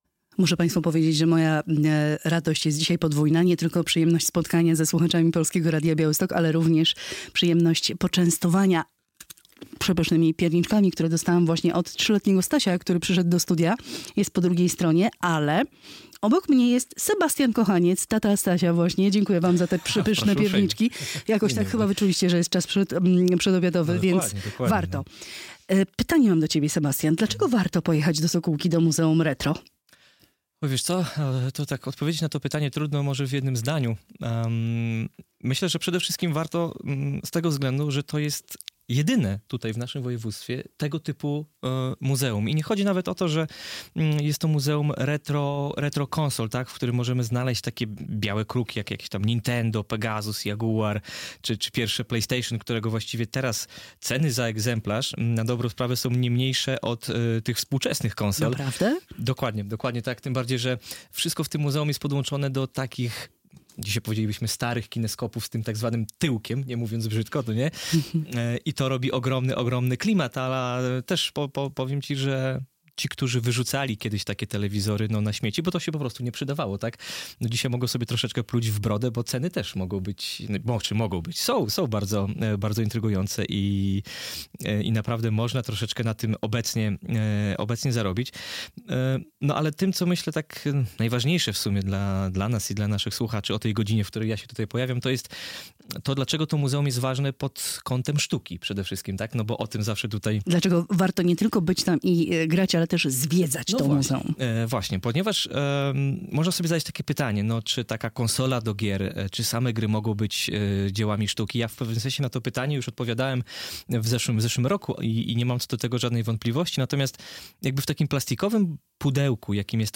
reportaż